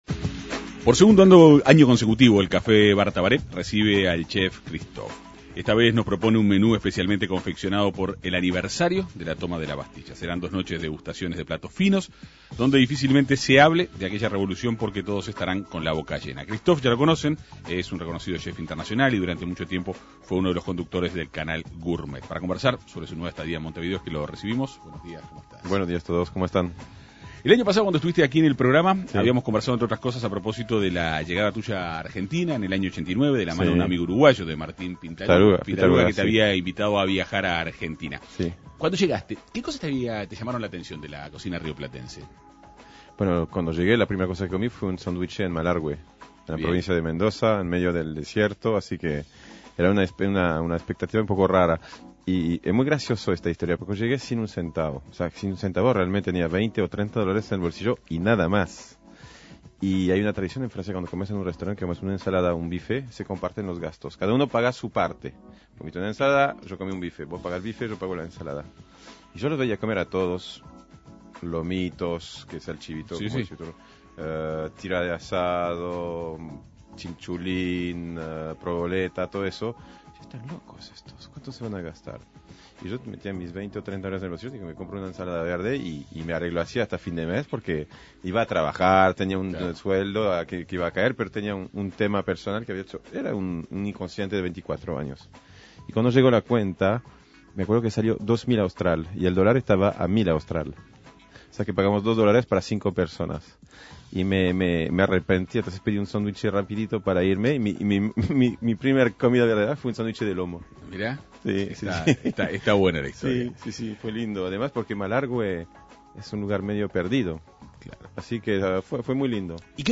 El chef internacional conversó en la Segunda Mañana de En Perspectiva.